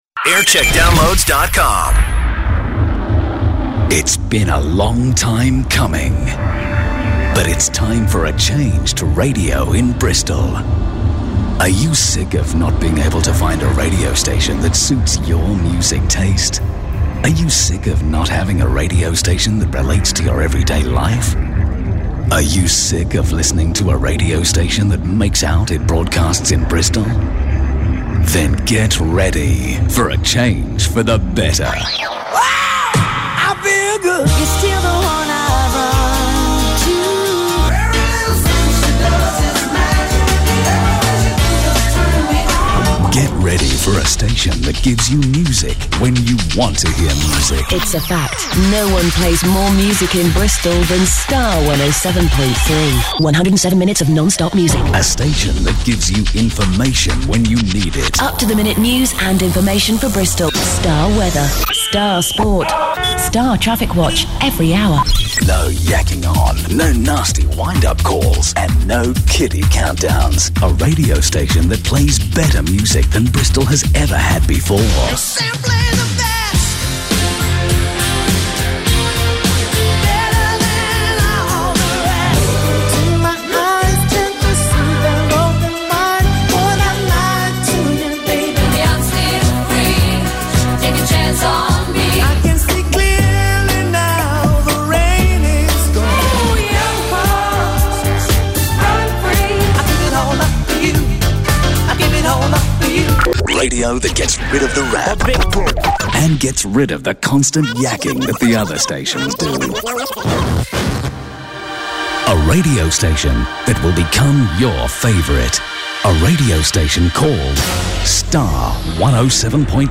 Launch Promo Jun 21st 2001 (2'07) - Star Bristol